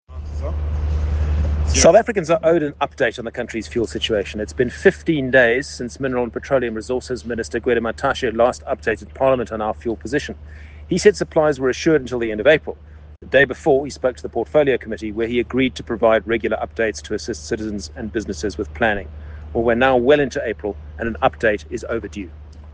Issued by James Lorimer MP – DA Spokersperson on Mineral & Petroleum Resources